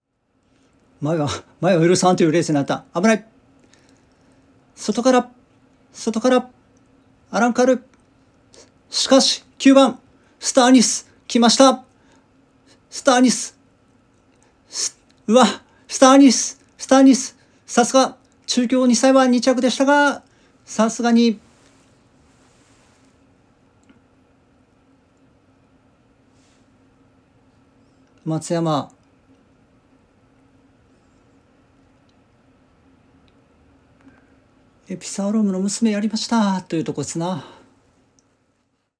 【阪神ＪＦ】（阪神）スターアニスが鮮やかな末脚で混戦を断ち切って２歳女王に輝く｜競馬実況web｜競馬｜ラジオNIKKEI